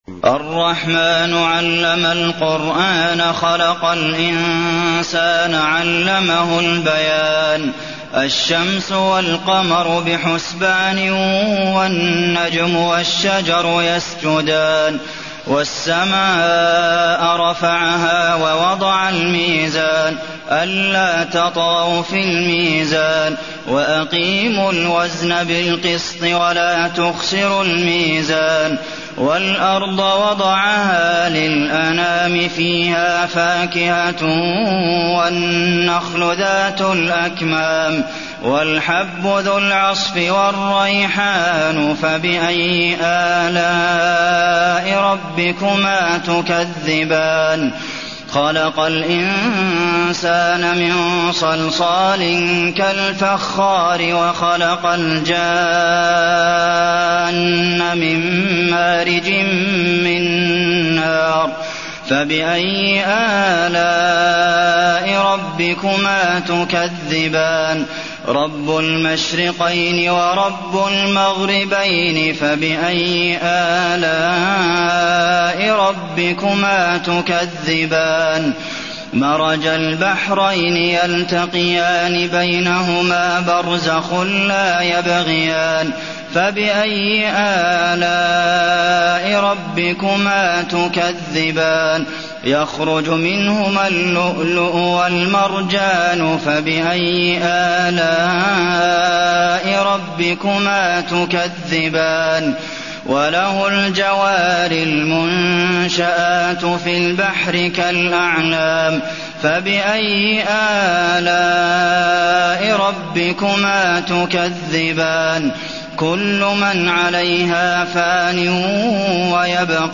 المكان: المسجد النبوي الرحمن